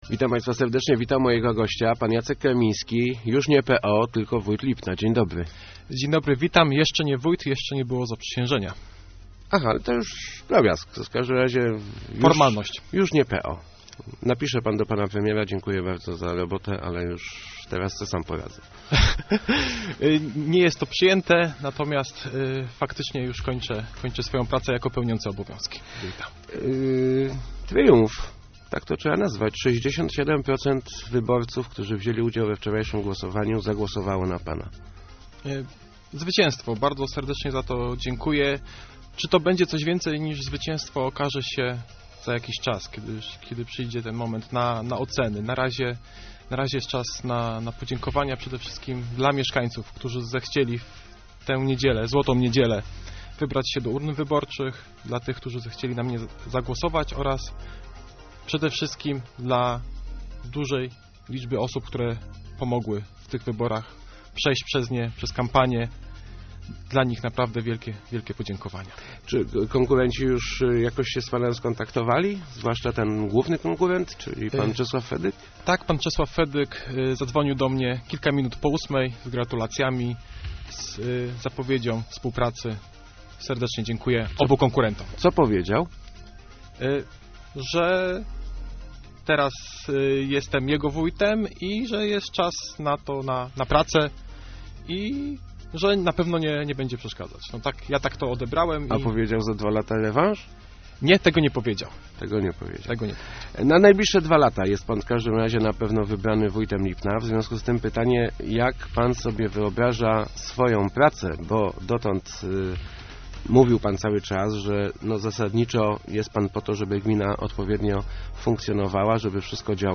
Nie będzie żadnych czystek w urzędzie - mówił w Rozmowach Elki Jacek Karmiński. Nowy wójt Lipna zapowiedział też, że nie powoła zastępcy.